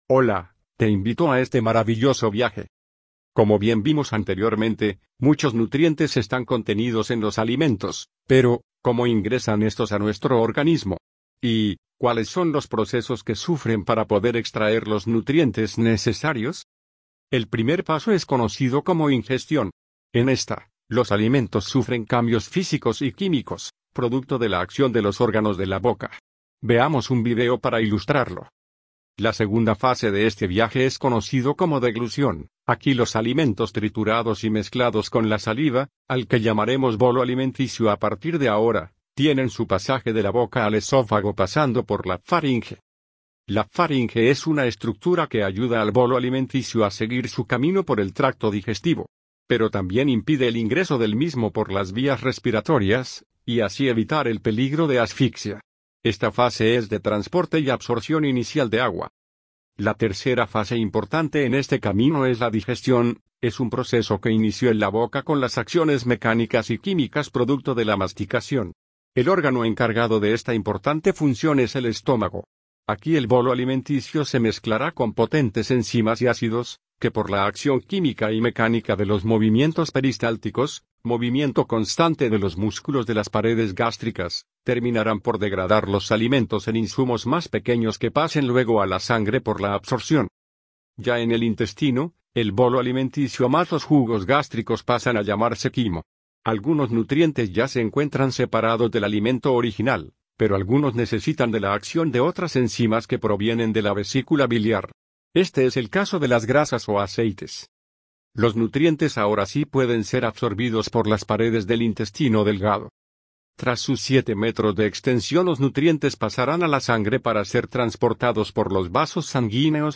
Animaker_Voice.mp3